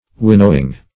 Winnowing \Win"now*ing\, n.